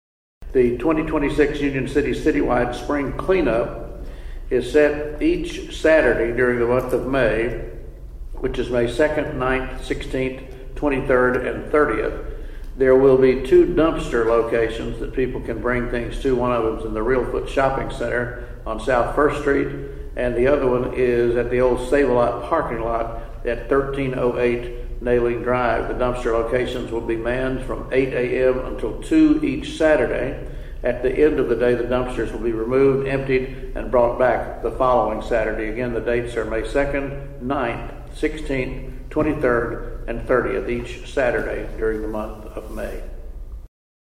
The annual Union City Spring Clean-Up was announced at this week’s City Council meeting.
Mayor Terry Hailey explained this year’s city-wide event.(AUDIO)